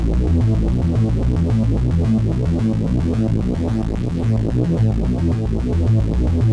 Index of /musicradar/dystopian-drone-samples/Droney Arps/110bpm
DD_DroneyArp2_110-A.wav